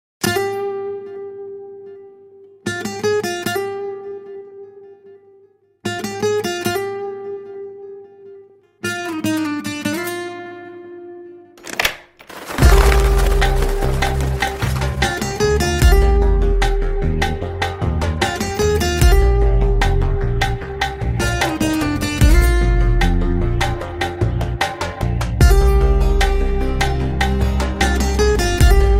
emotional Indian ballad